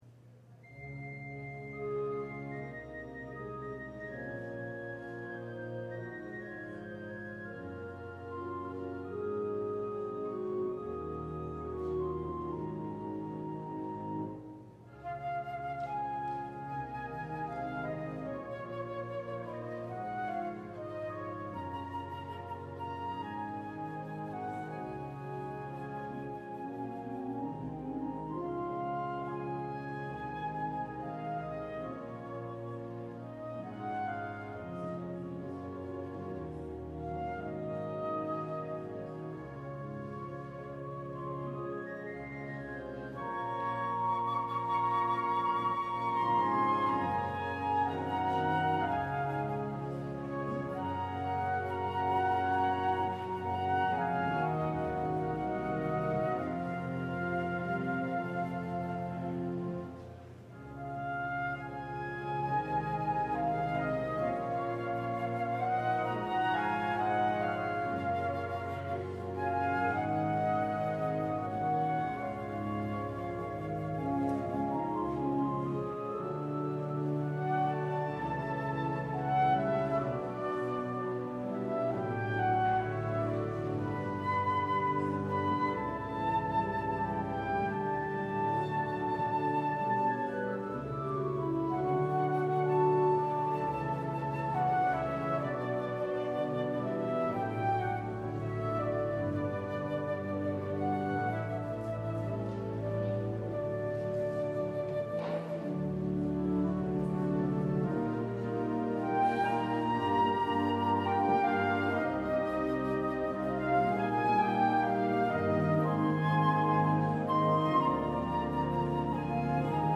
LIVE Midday Worship Service - The Image of the Invisible God: Friends
Congregational singing—of both traditional hymns and newer ones—is typically supported by our pipe organ.